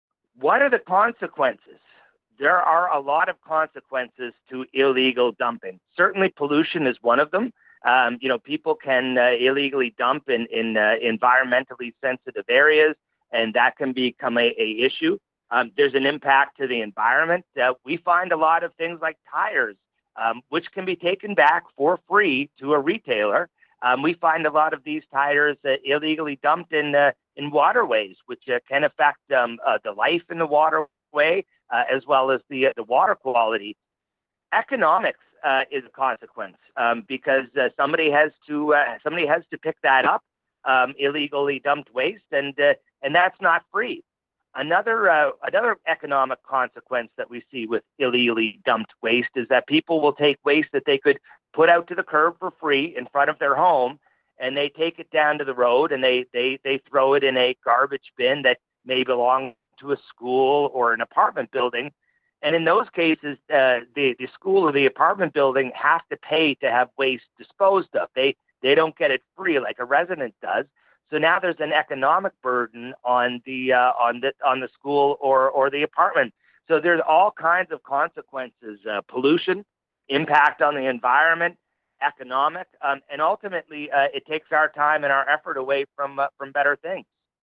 nous explique ce qu’il en est à l’occasion de l’entretien qu’il a accordé à OUI 98,5 FM